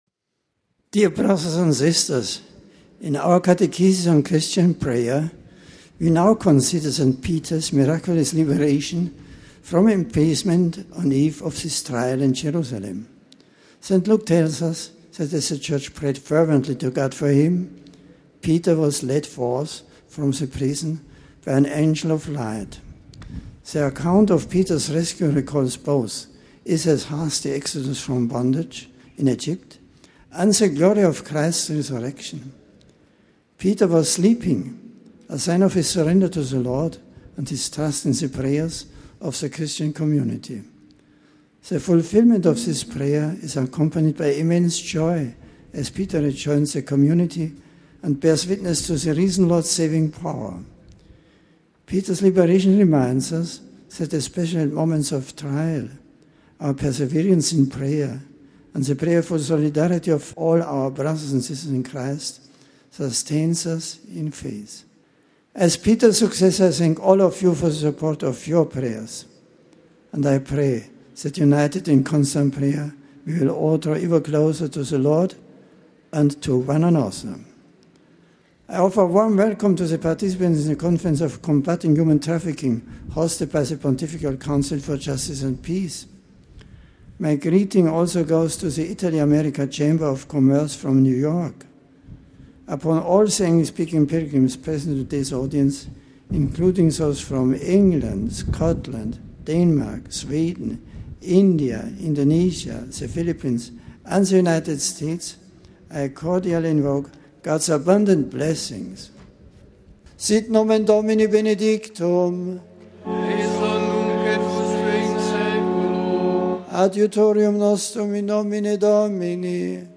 The general audience of May 9th was held outdoors in St. Peter’s Square in the Vatican.
An aide greeted the Pope on behalf of the English speaking pilgrims, introducing the various groups to him. Pope Benedict then delivered a discourse in English